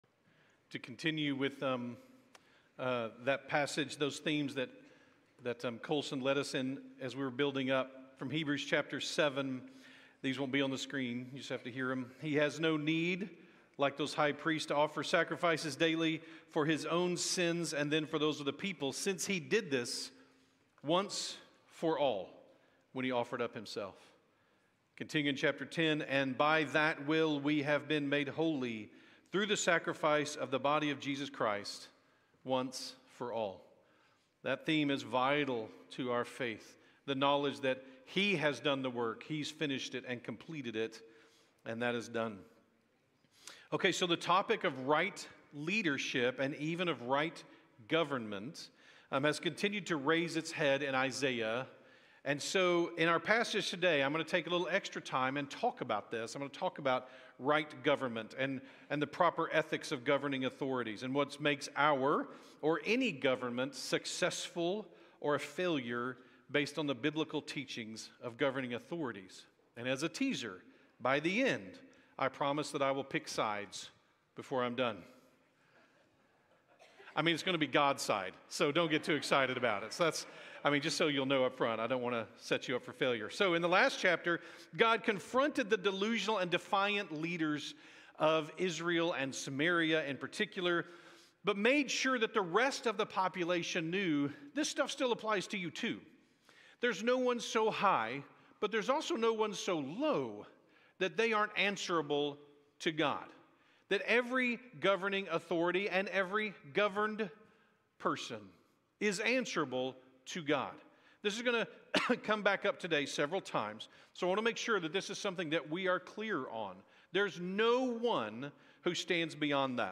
by South Spring Media | Oct 12, 2025 | 2025 Sermons, Isaiah, Isaiah Series | 0 comments